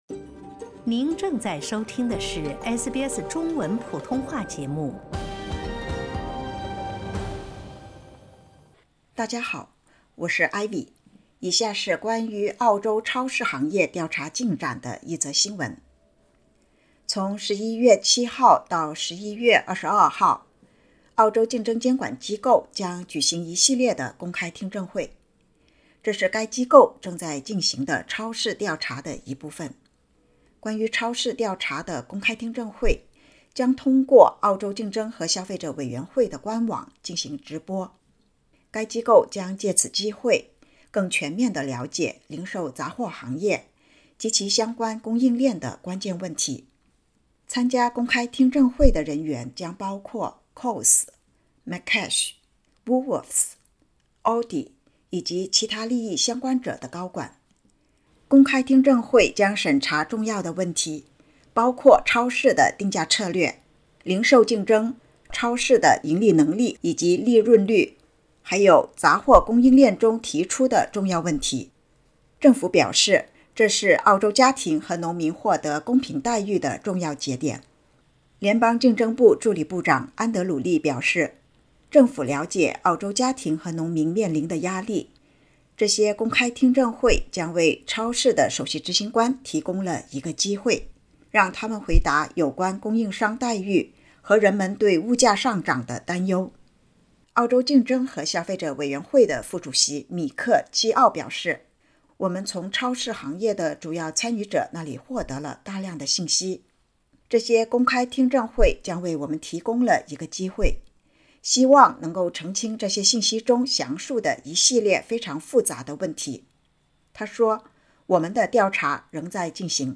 （点击音频，收听详细报道）